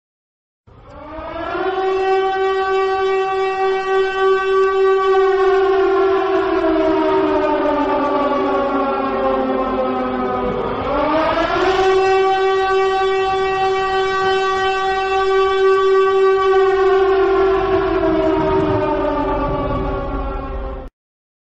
На этой странице собраны звуки сиреноголового — жуткие аудиозаписи, создающие атмосферу страха и неизвестности.
Звук воздушной тревоги Сиреноголового